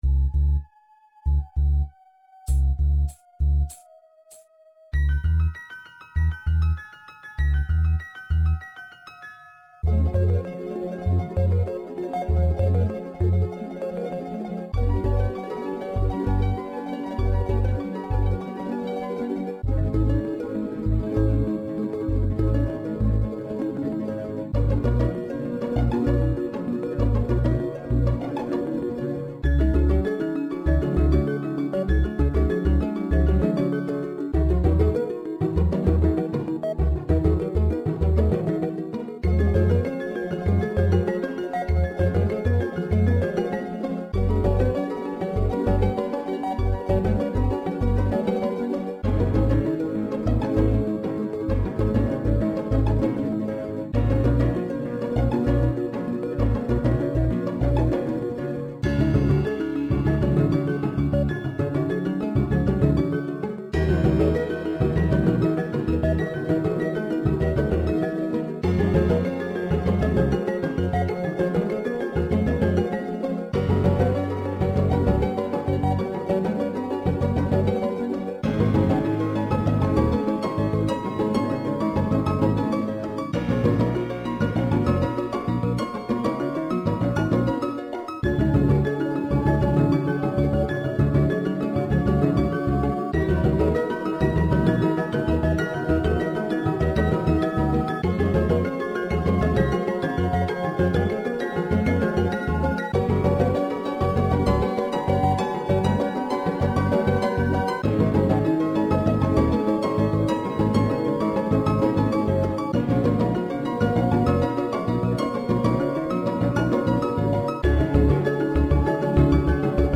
Nieuw muziekje, met oa piano en harp.
en in snellere uitvoering.